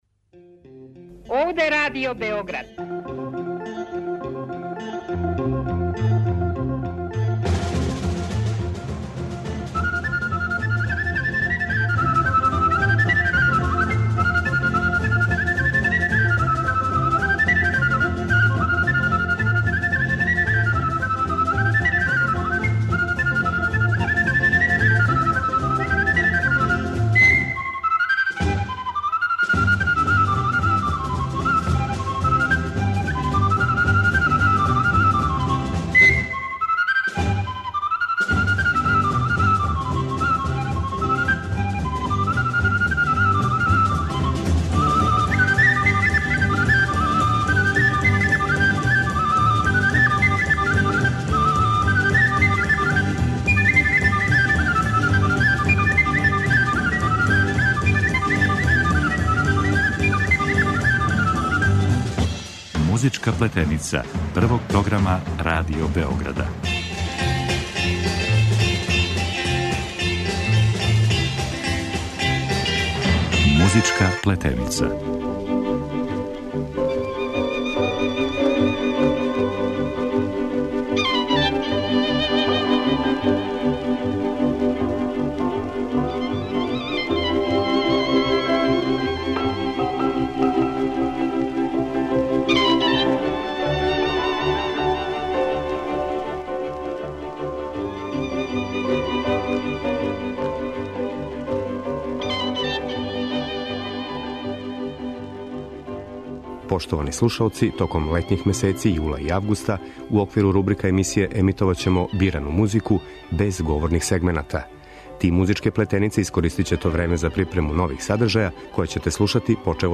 Током летњих месеци у оквиру рубрика емисије емитоваћемо бирану музику, без говорних сегмената.